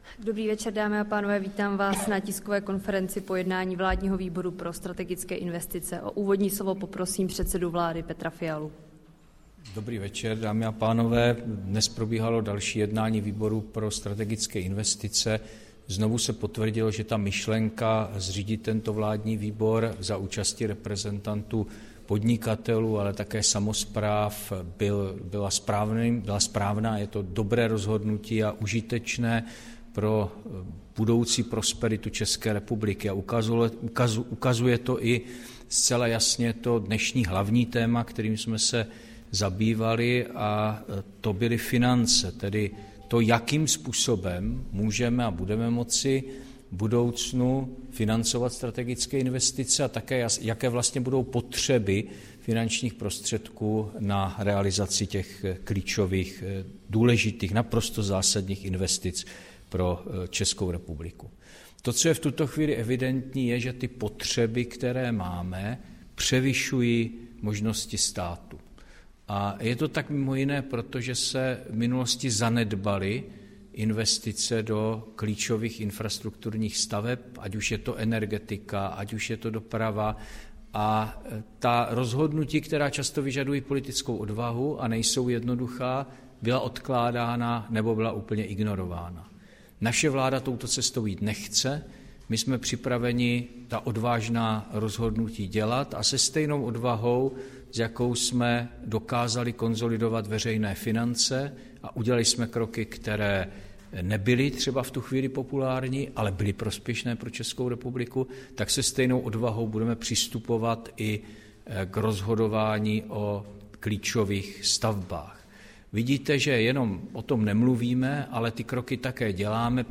Tisková konference po jednání Vládního výboru pro strategické investice, 14. února 2024